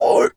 pgs/Assets/Audio/Animal_Impersonations/seal_walrus_2_hurt_01.wav at master
seal_walrus_2_hurt_01.wav